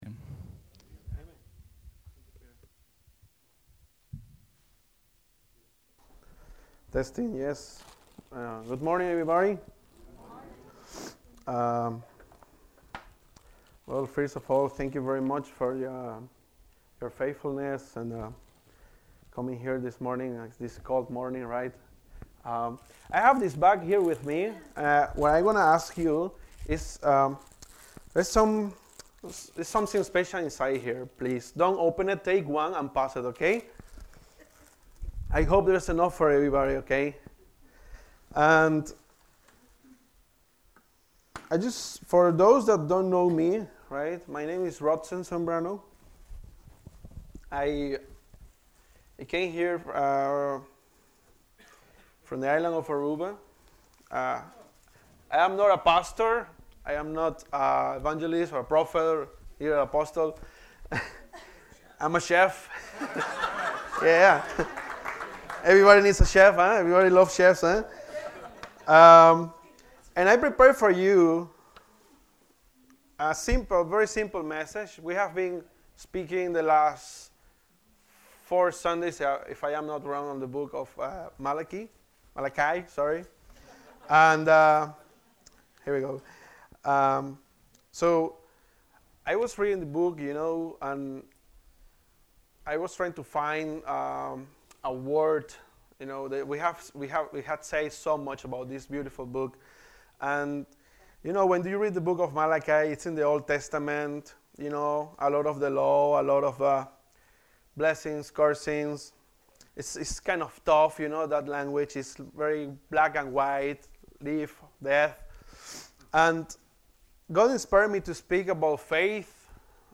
Message: “Faith & Promises”